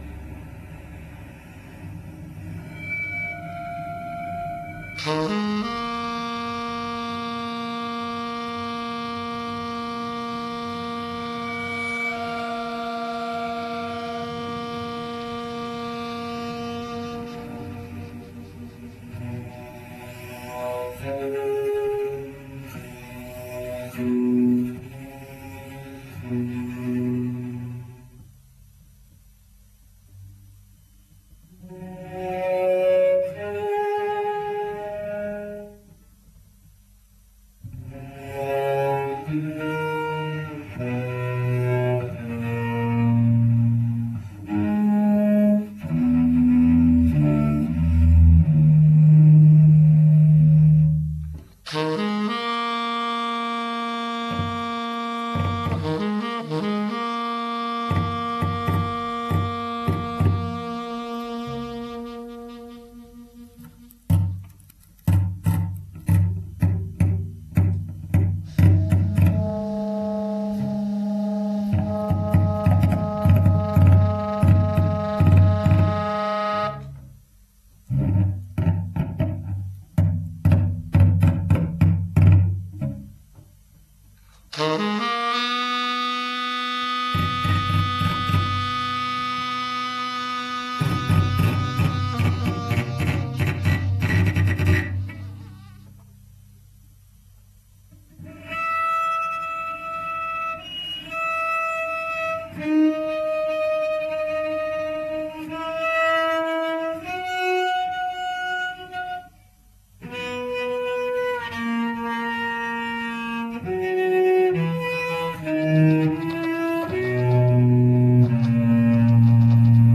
Requiem :
Violoncelle pour les tranches contenant uniquement des parties bois. Saxophone pour les tranches contenant uniquement des parties métal. Violoncelle et saxophone pour les tranches contenant des parties bois et métal.
Musicien saxophoniste.
Musicienne violoncelliste.